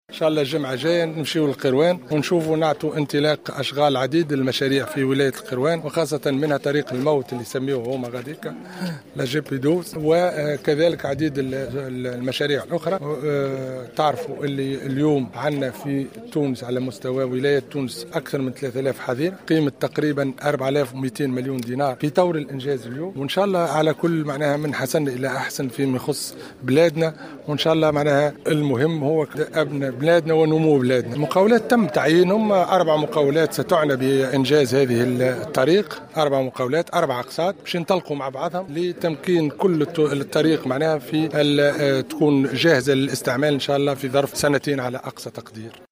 أكد وزير التجهيز والاسكان محمد صالح العرفاوي صباح اليوم الأربعاء 04 أوت في تصريح لمراسل الجوهرة "اف ام" على هامش زيارة إلى 2016 ماطر التابعة لولاية بنزرت أنه سينتقل الأسبوع القادم إلى ولاية القيروان لإعطاء اشارة انطلاق عدد من المشاريع المعطلة هناك وخاصة منها أشغال تهيئة "طريق الموت" الرابط بين سوسة والقيروان.